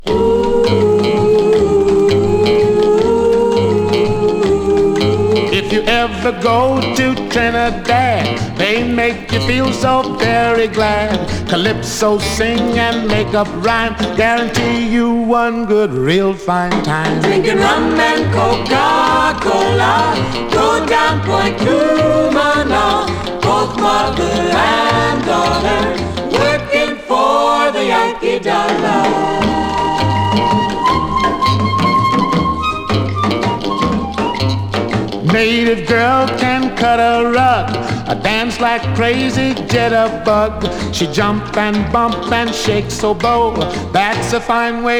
シンプルで小技の効いた演奏、個性を放つサックスにギター。
R&B, Jazz, Jump　France　12inchレコード　33rpm　Mono